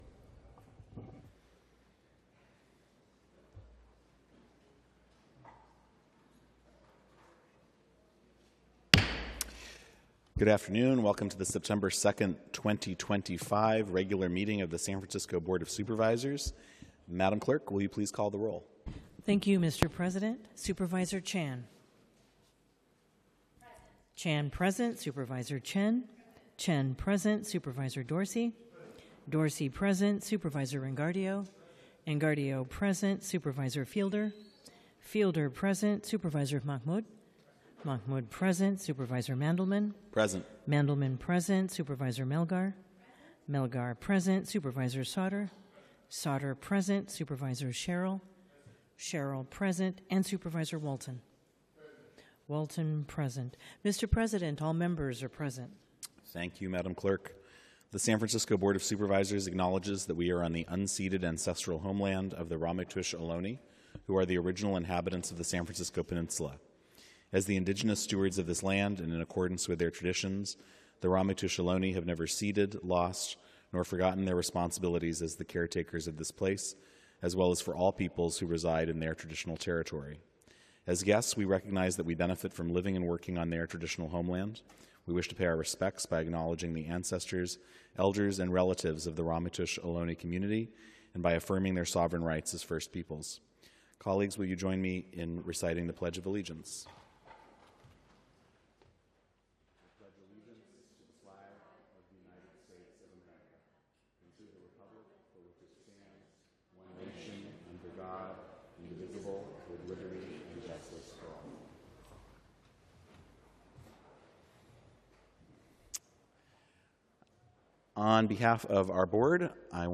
BOS Board of Supervisors - Regular Meeting - Sep 02, 2025